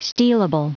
Prononciation du mot stealable en anglais (fichier audio)
Prononciation du mot : stealable